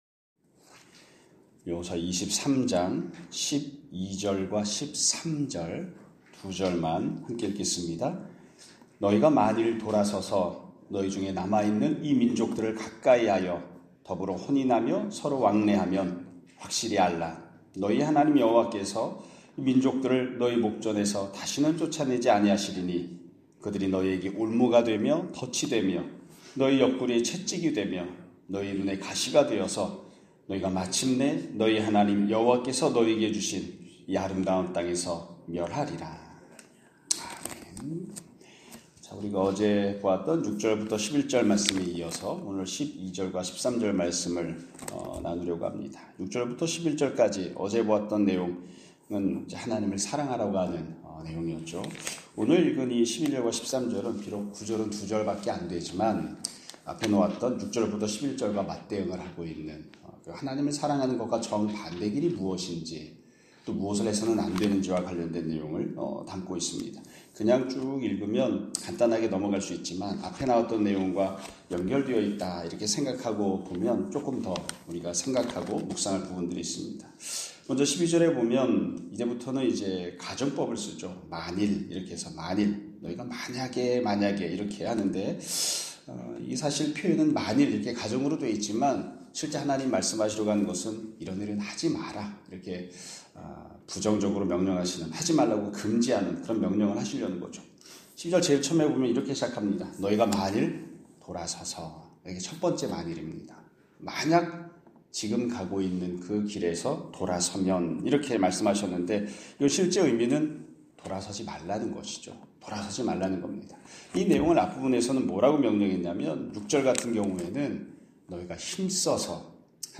2025년 2월 19일(수요일) <아침예배> 설교입니다.